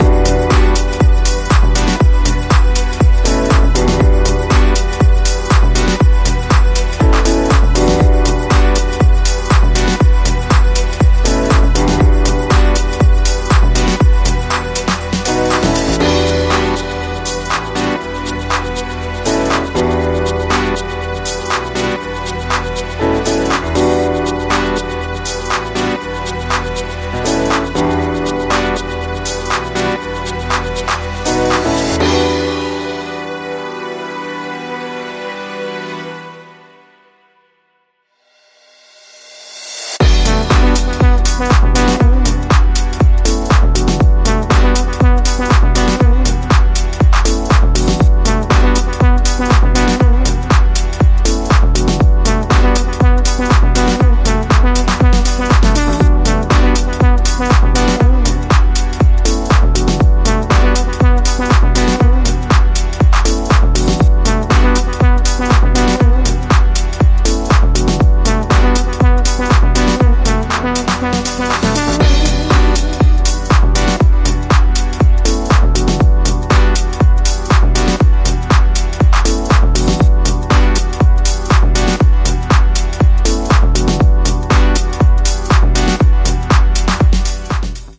ここでも、今までのように程よくエレクトリックな音色を用いながらモダンでウォームなディープ・ハウスを展開。